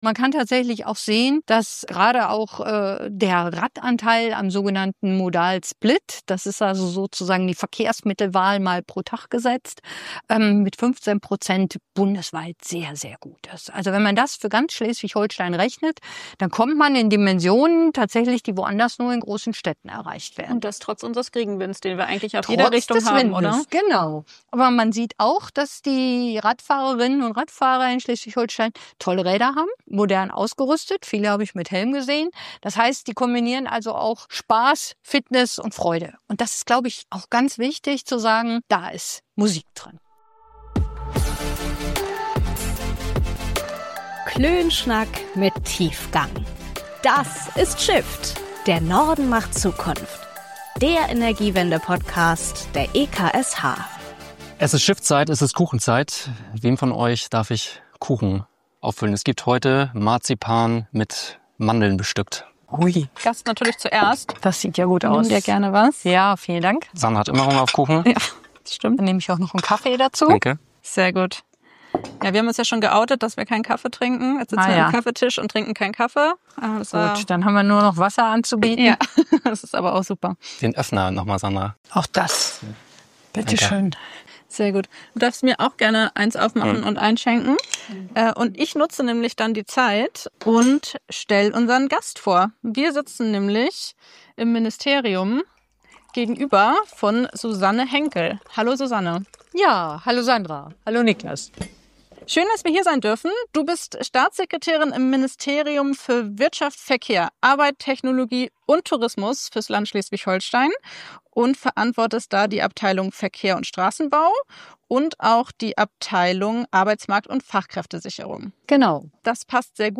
Denn dort arbeitet seit Mai 2025 unser heutiger Gast: Schleswig-Holsteins Staatssekretärin für Verkehr und Arbeit, Susanne Henckel.
Viel Spaß bei unserem Klönschnack mit Tiefgang!